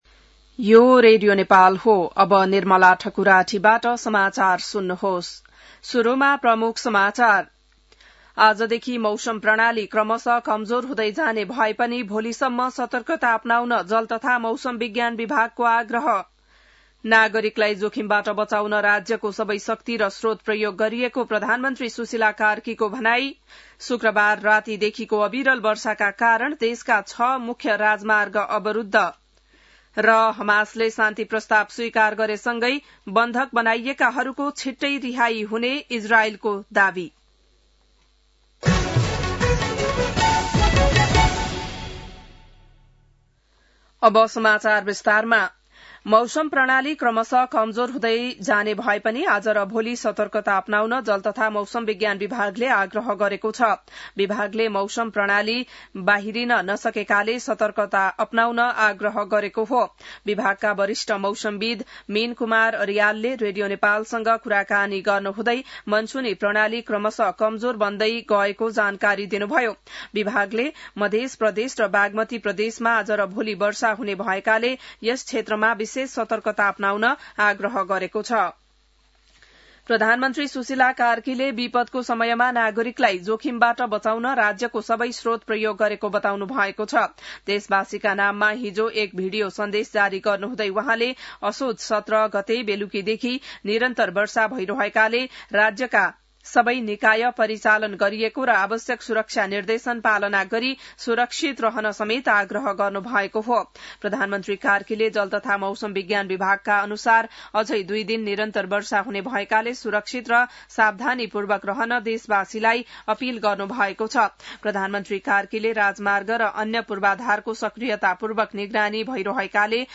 बिहान ९ बजेको नेपाली समाचार : २७ वैशाख , २०८२